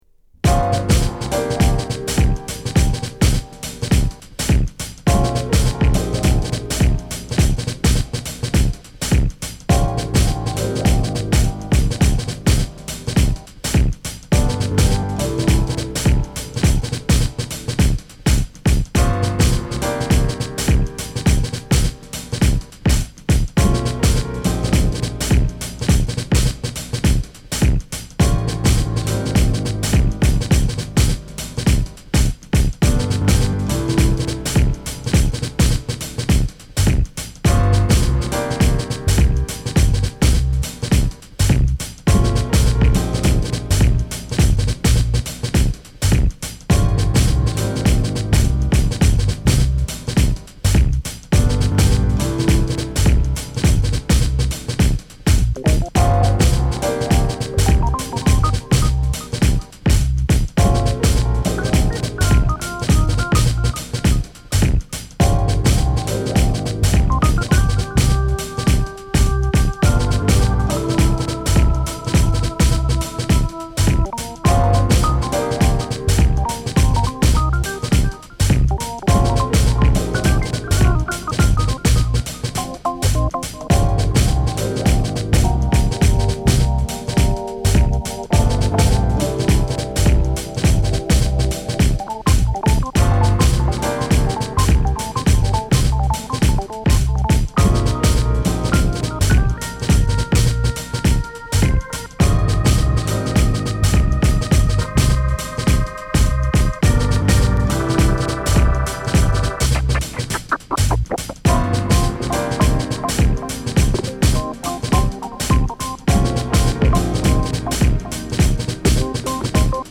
鮮やかなピアノのコード・フレーズが心地よいロウなブレイク・グルーヴに